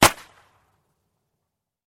Clapper sound and confetti
• Category: Clapper
• Quality: High